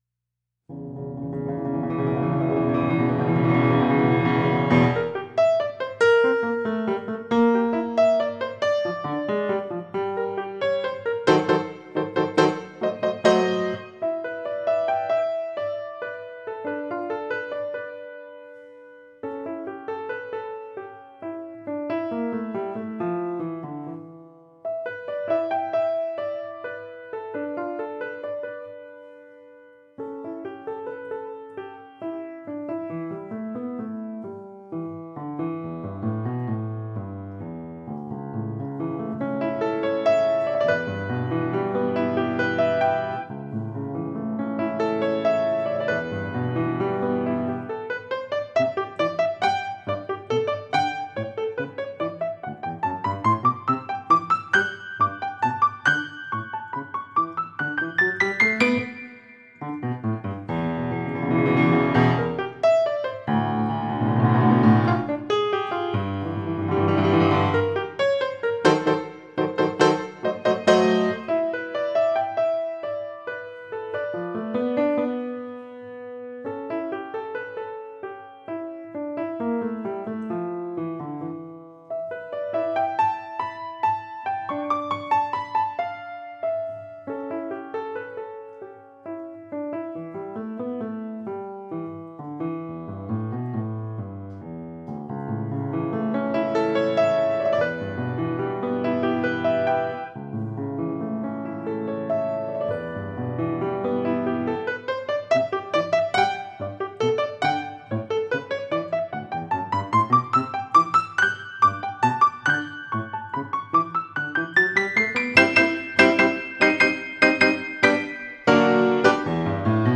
Piano, Música pedagogica